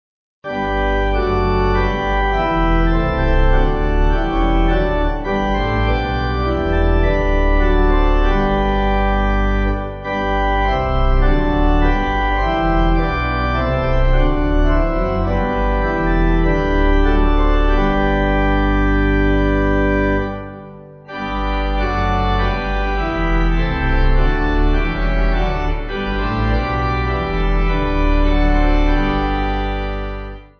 Organ
(CM)   6/G